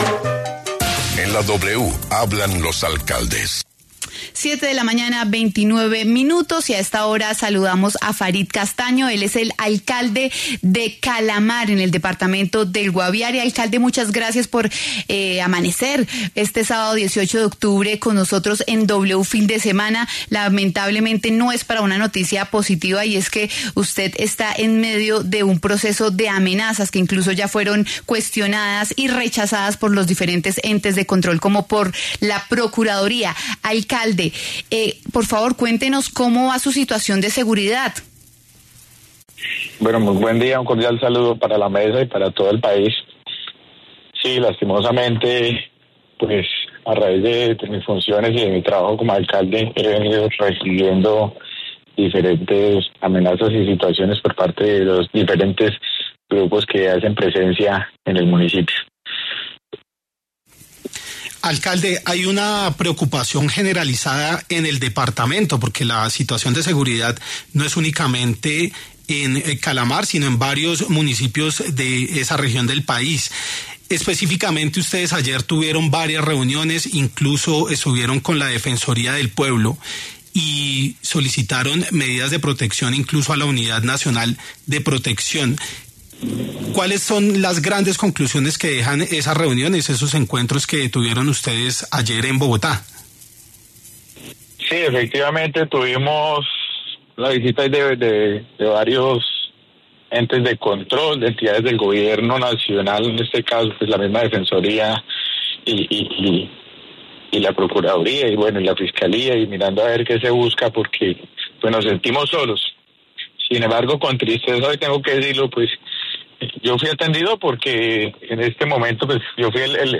Farid Castaño, alcalde de Calamar (Guaviare), habló en W Fin de Semana de las amenazas que ha recibido por parte de grupos criminales y pidió, nuevamente, protección al Gobierno Nacional.